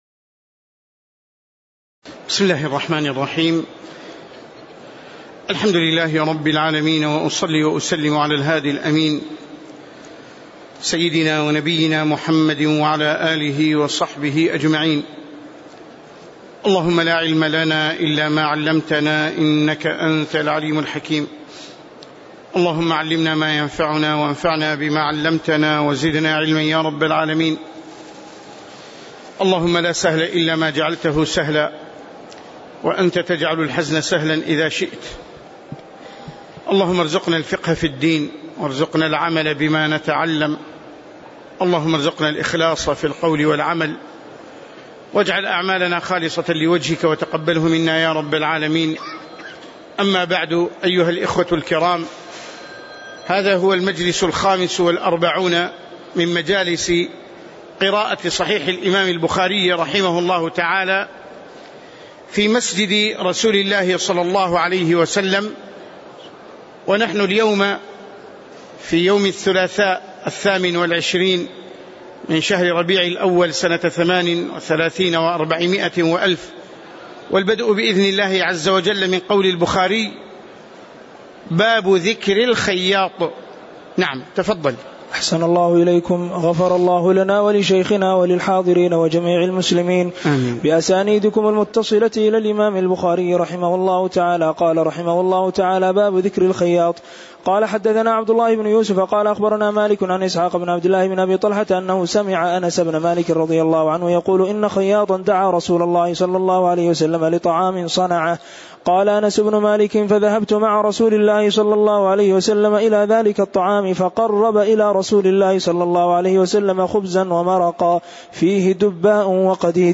تاريخ النشر ٢٨ ربيع الأول ١٤٣٨ هـ المكان: المسجد النبوي الشيخ